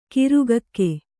♪ kirugakke